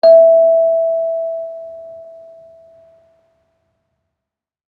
kalimba1_circleskin-E4-pp.wav